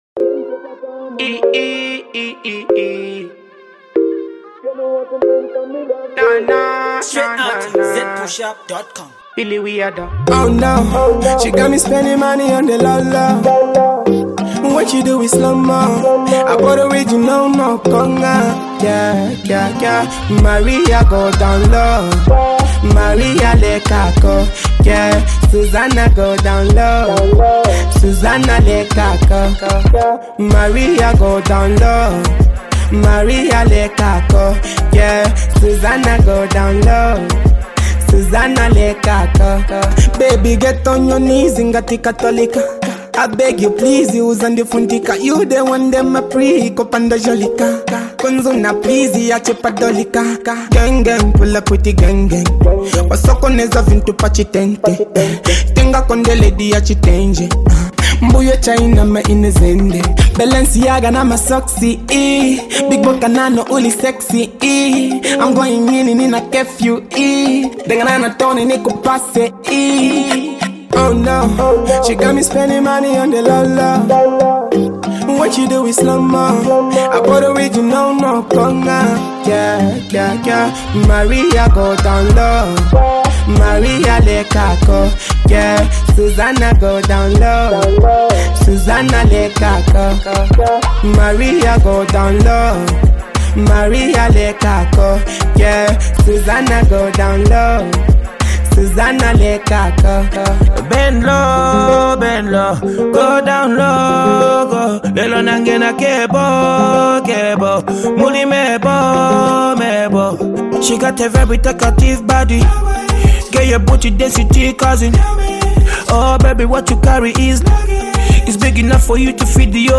clean dancehall record